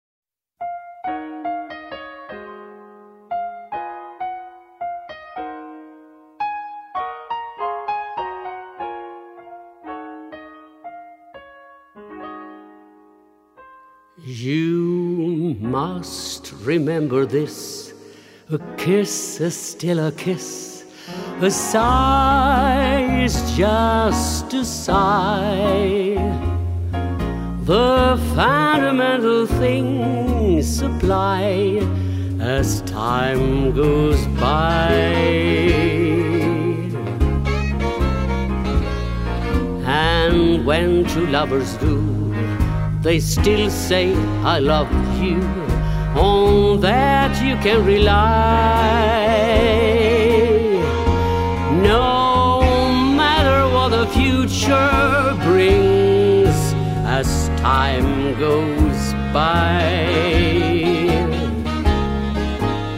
Broadway Musik